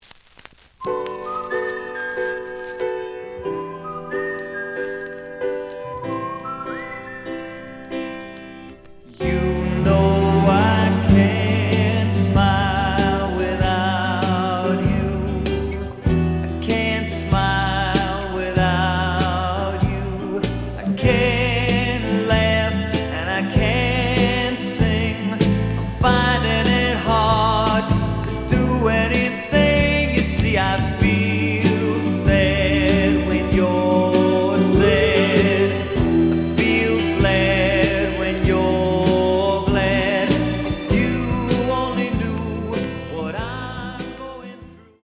The audio sample is recorded from the actual item.
●Genre: Rock / Pop
Some noise on parts of A side.)